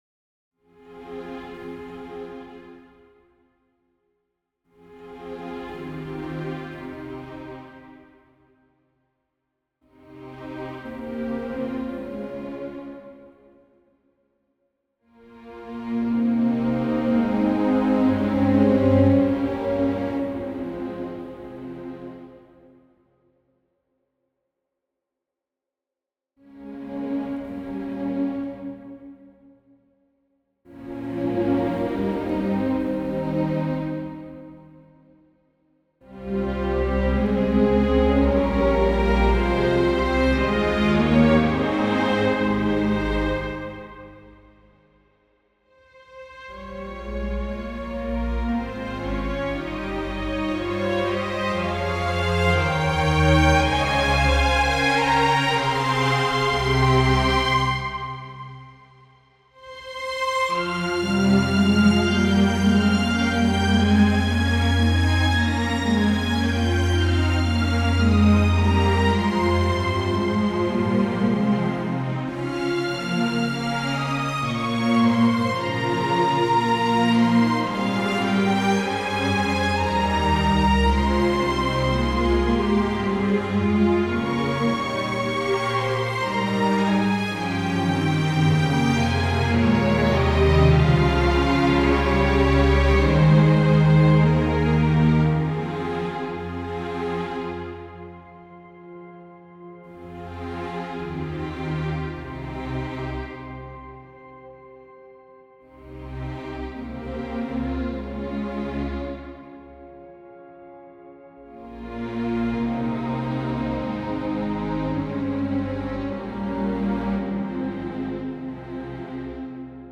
Written as part of my Master’s thesis, the piece is scored for string orchestra and explores modal writing as its central compositional focus. The piece reflects an intense and dark emotional story, and weaves modal textures into a poignant string soundscape.